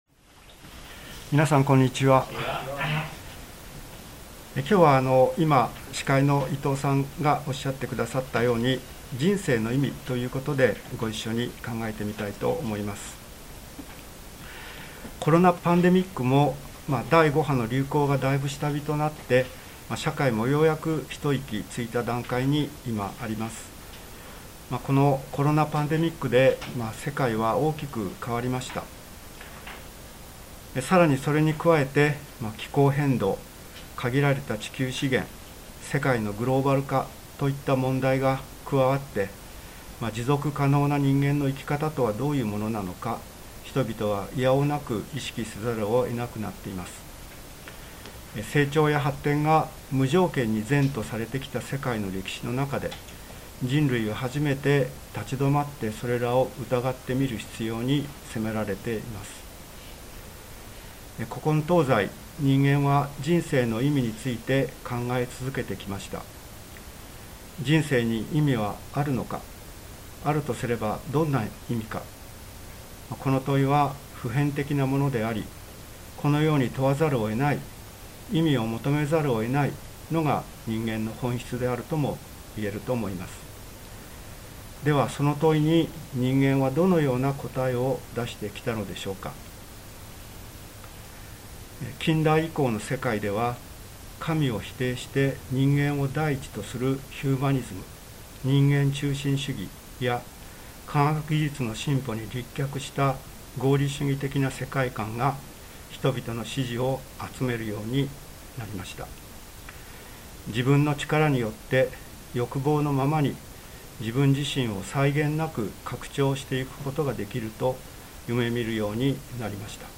聖書メッセージ 人生の意味